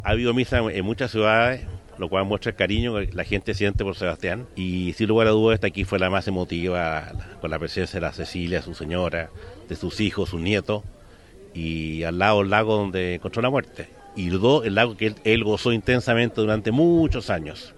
El hermano del expresidente, Pablo Piñera Echenique, indicó que las misas que se realizaron en el país demuestran el cariño que sentía la gente por Sebastián Piñera.